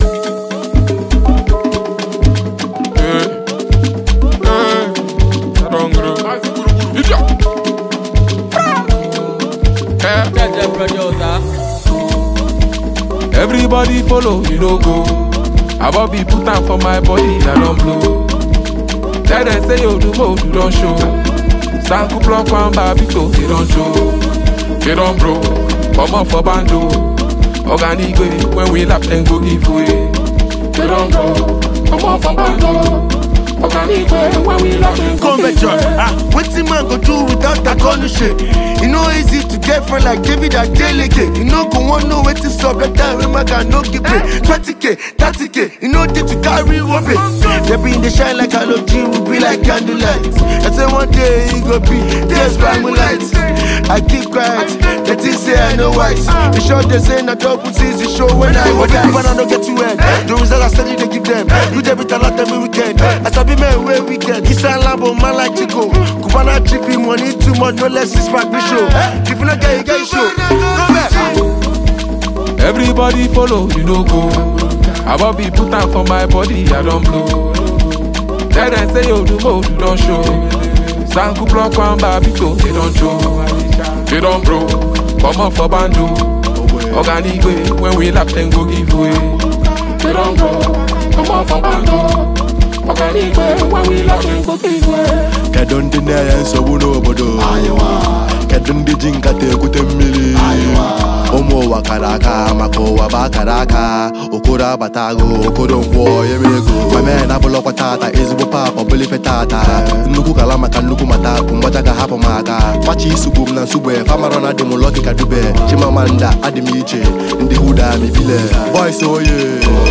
catchy song